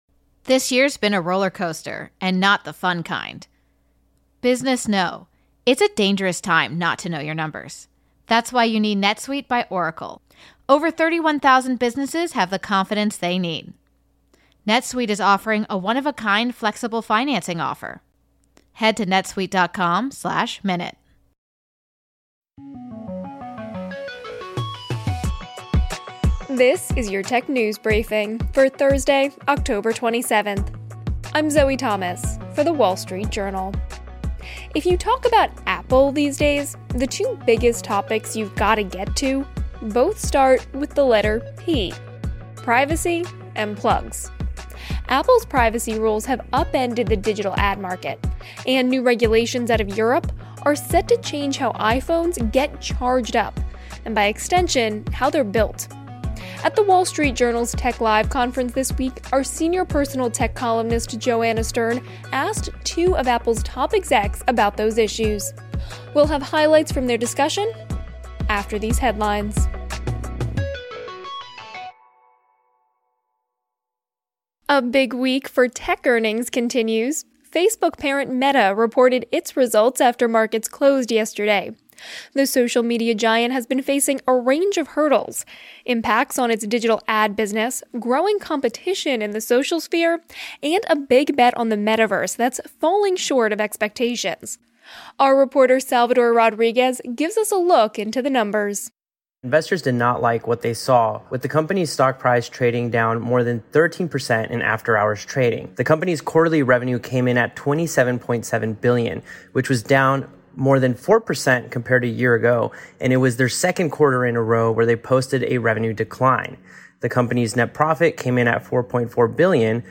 Apple is at the center of two big shifts in tech: privacy rules that have shaken the digital ad market and Europe’s move toward a common charging standard for devices. At the WSJ’s Tech Live conference this week, Apple executives Craig Federighi and Greg Joswiak spoke with WSJ Senior Personal Tech Columnist Joanna Stern about those hot-button topics.